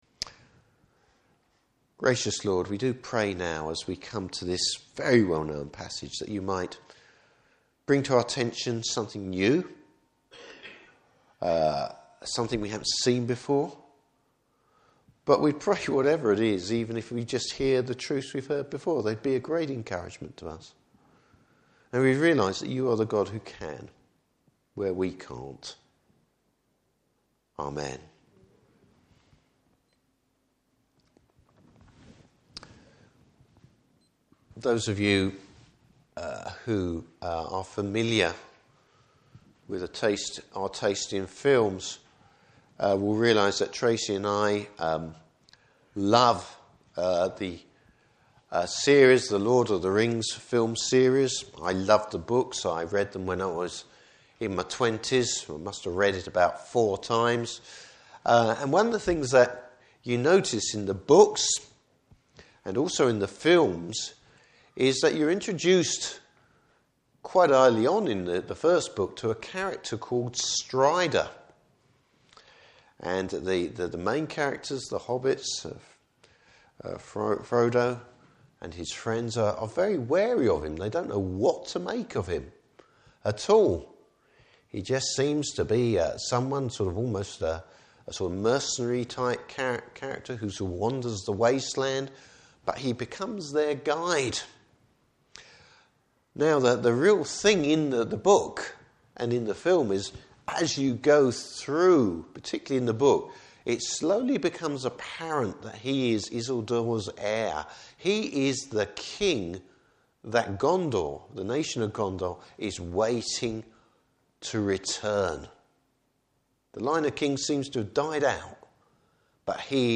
Passage: Isaiah 9:1-7. Service Type: Morning Service God bucks the odds!